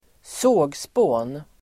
Uttal: [²s'å:gspå:n]